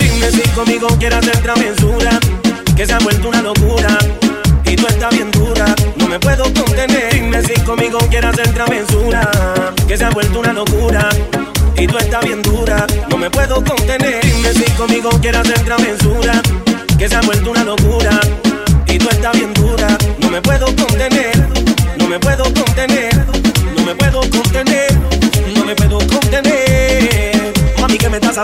Genere: latin, reggaeton